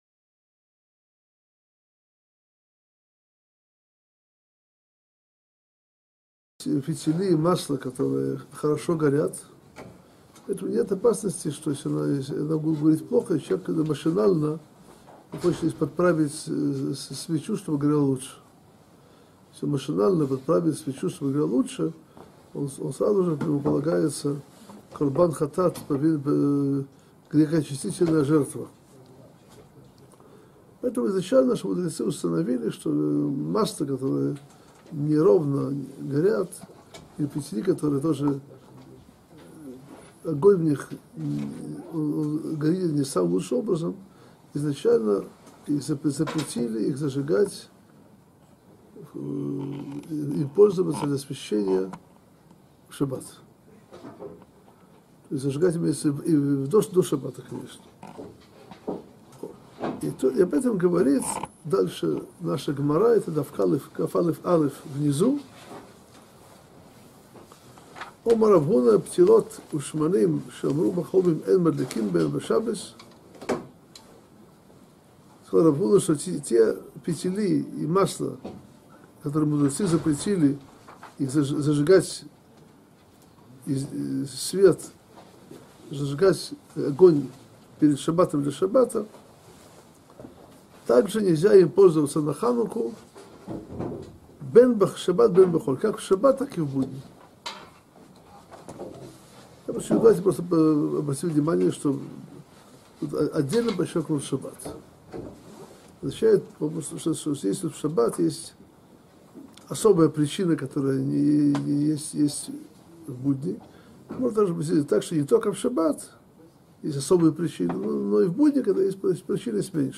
Урок